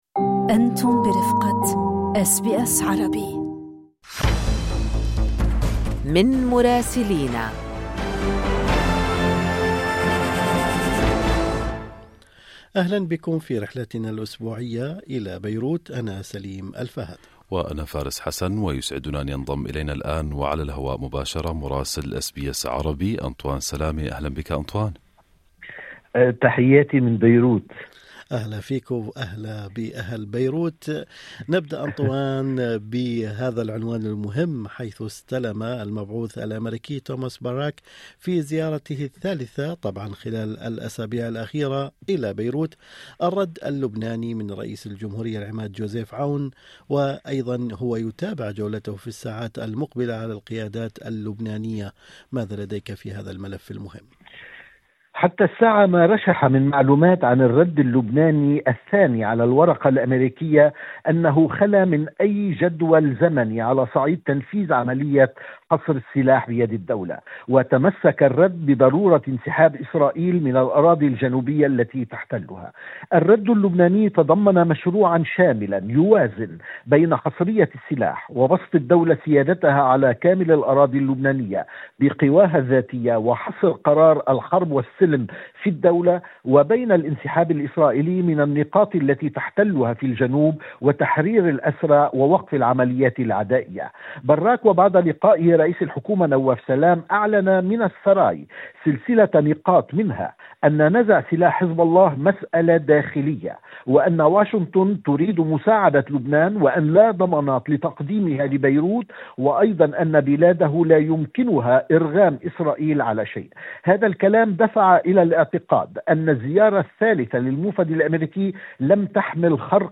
الرد اللبناني يتسلمه المبعوث الأمريكي توماس باراك وأخبار أخرى في تقرير مراسلنا في بيروت